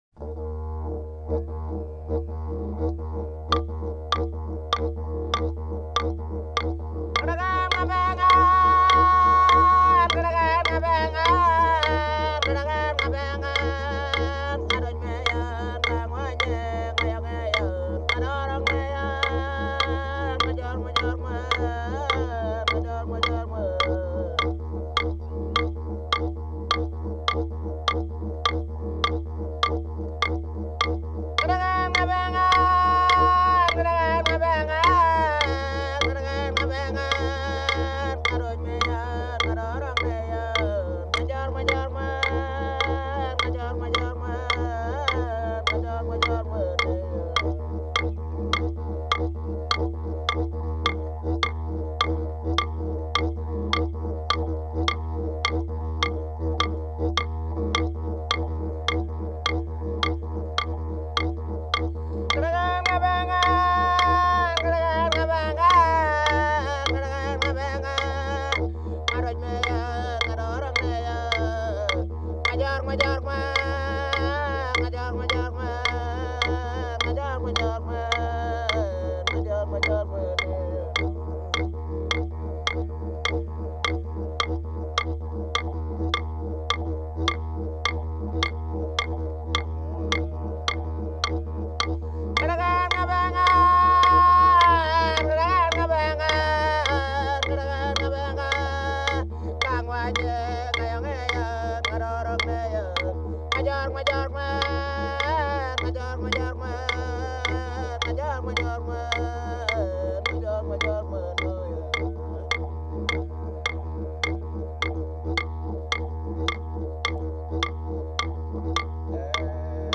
Música aborigen australiana